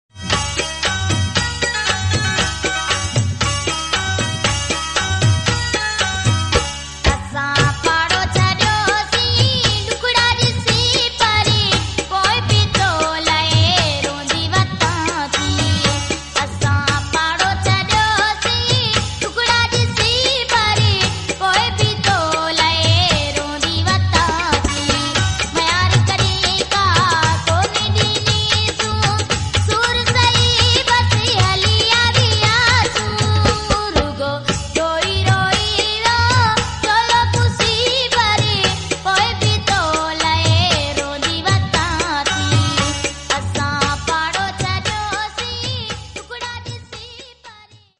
Sindhi Marwari Song